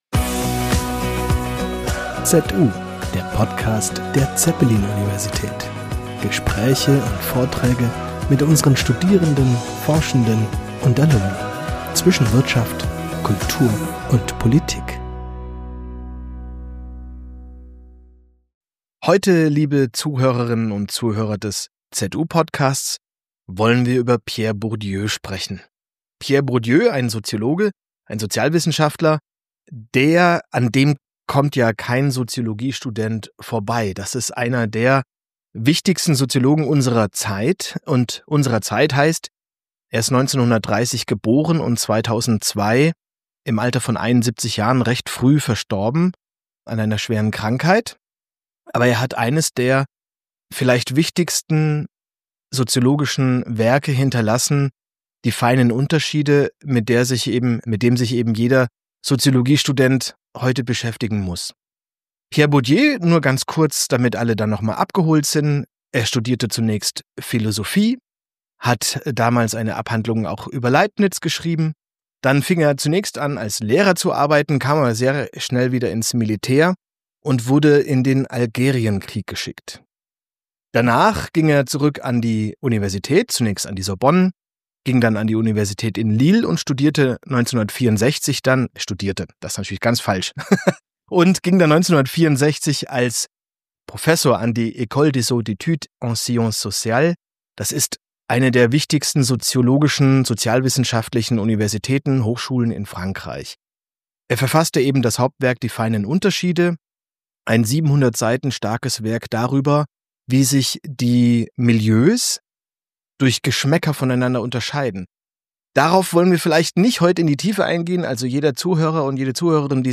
Science Talk